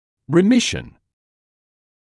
[rɪ’mɪʃn][ри’мишн]ремиссия (временное ослабление болезни)